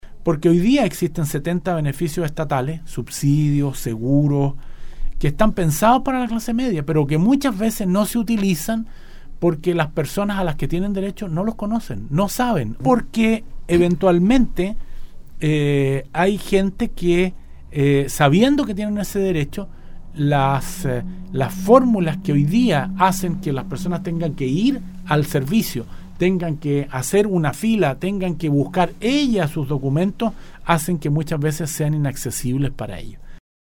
El Ministro de Minería, Baldo Prokurica estuvo en los estudios de Nostálgica, donde pudo profundizar en la reciente presentación que efectuó el Presidente de la República, del programa llamado Clase Media Protegida.